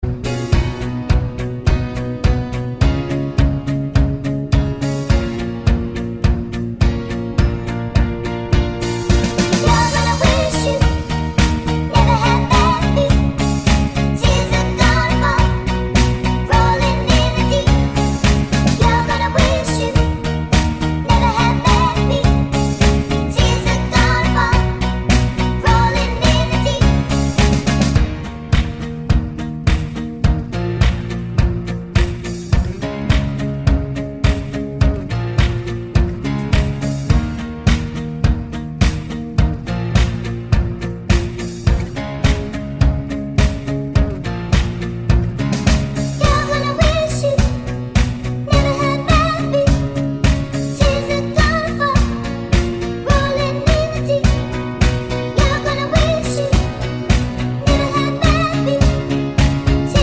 Up 3 Semitones Pop (2010s) 3:47 Buy £1.50